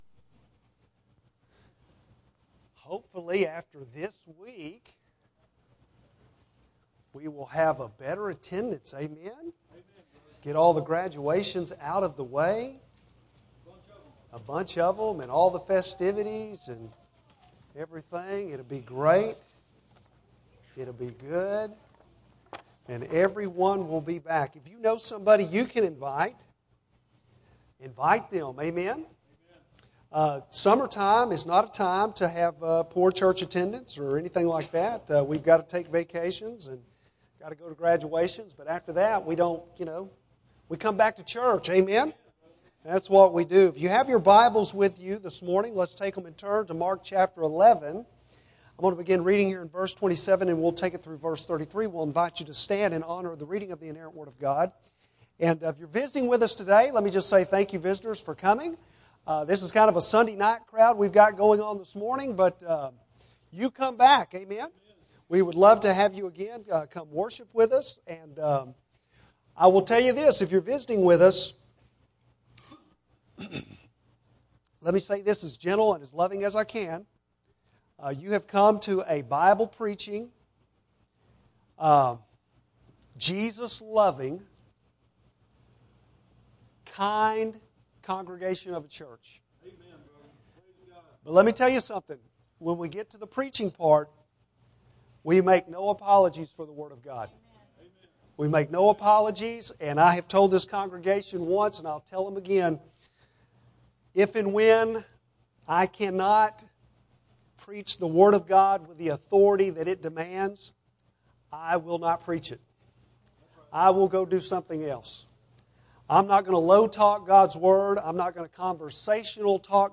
Bible Text: Mark 11:27-33 | Preacher